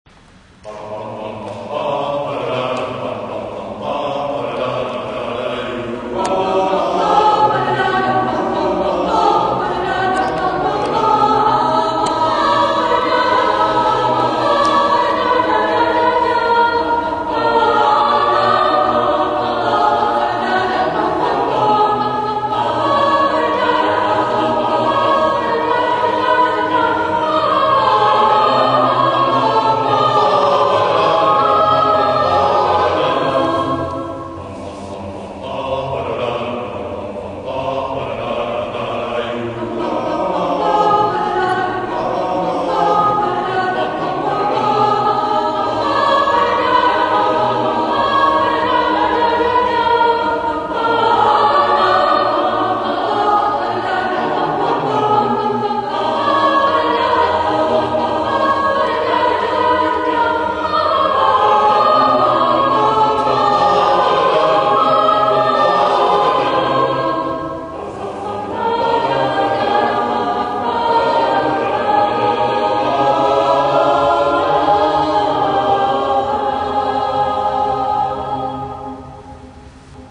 Zdraví vás smíšený pěvecký sbor Praeputium.
Ukázky z koncertu (WAV):